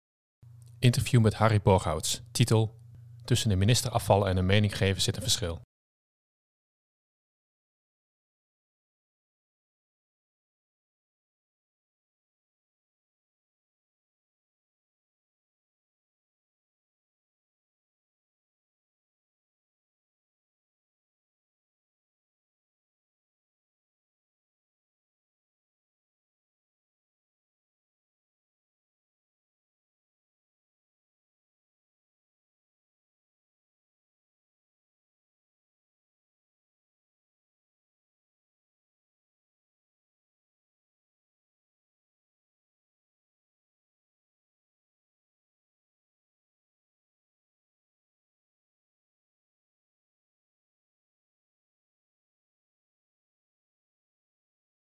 Interview met Harry Borghouts